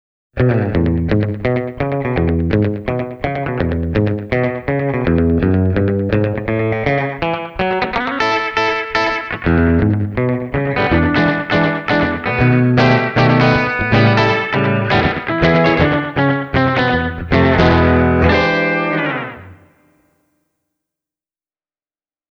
The Delay-section’s Drift-control makes it easy to simulate tape echo effects: